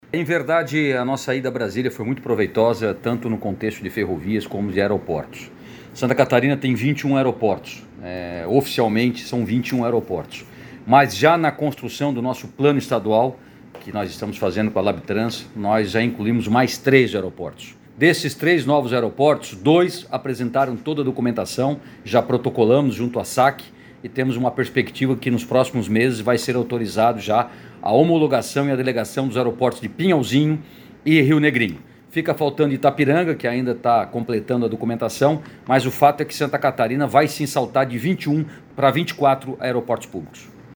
Para o secretário de Portos, Aeroportos e Ferrovias (SPAF), Beto Martin, estes aeroportos estão dentro do planejamento de ampliação do Estado.